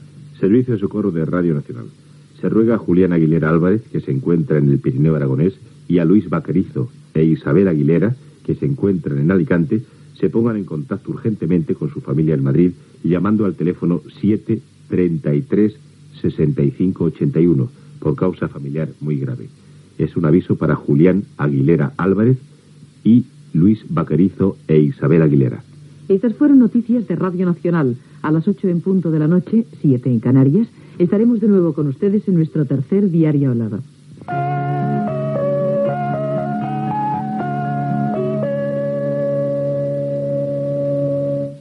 comiat del servei informatiu i sintonia de l'emissora
Informatiu